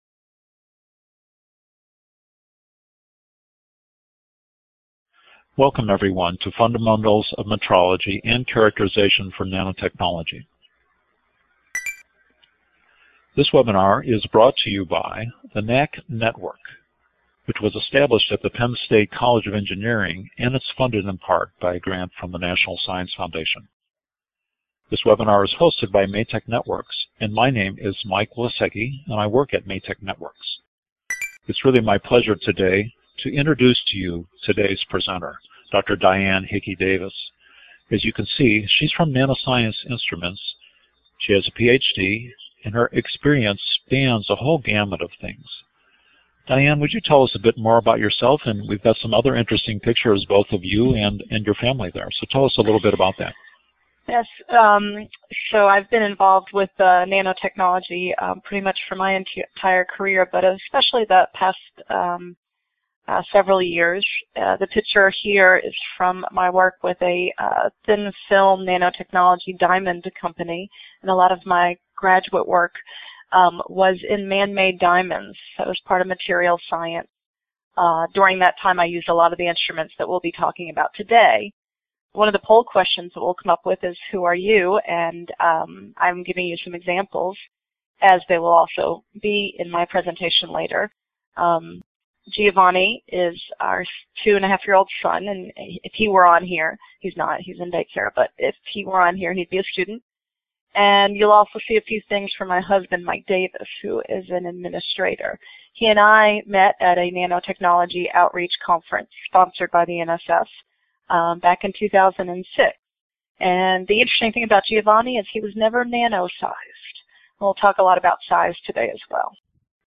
This webinar, provided by the Nanotechnology Applications and Career Knowledge Support (NACK) Center at Pennsylvania State University, provides an overview of the fundamentals of metrology and characterization for nanotechnology.